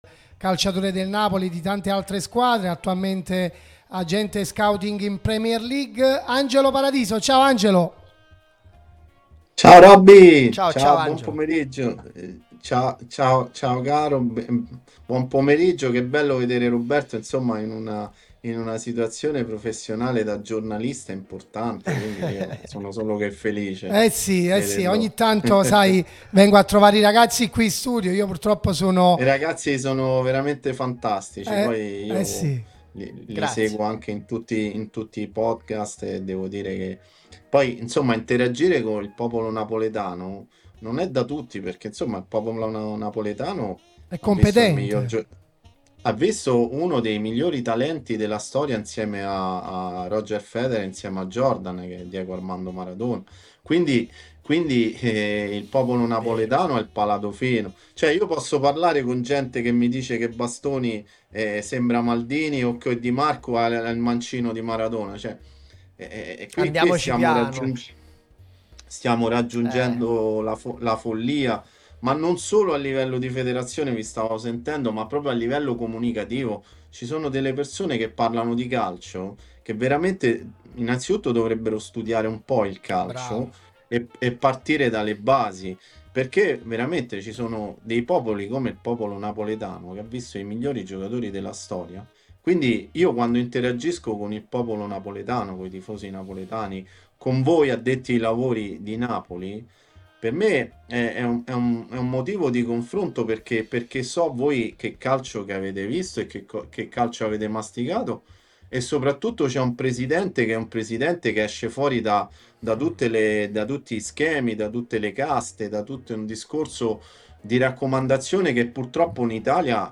Radio Tutto Napoli , l'unica radio tutta azzurra e sempre live